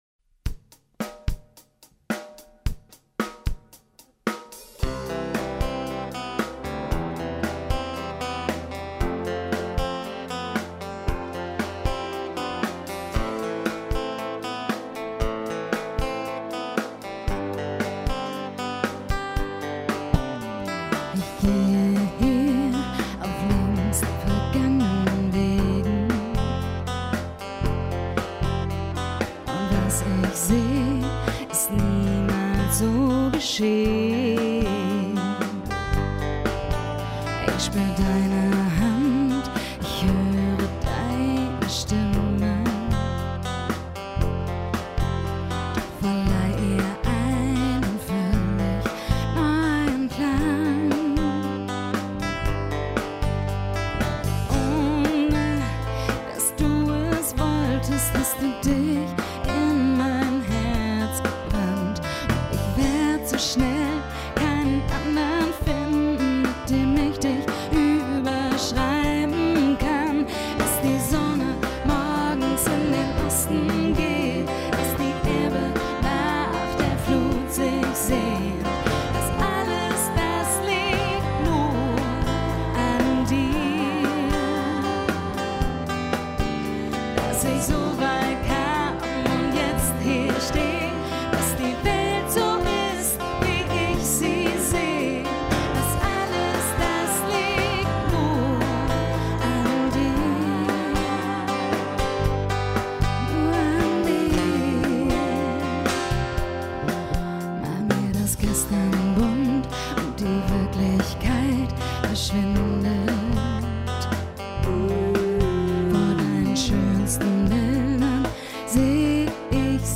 Gitarre
Bass
Gesang
Keyboards
Demo Songs: